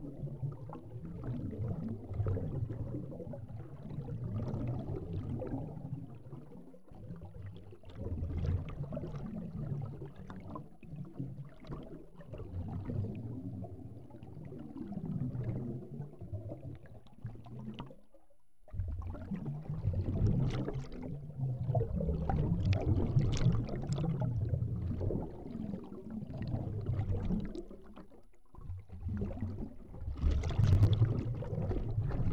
BUBBLE AM00L.wav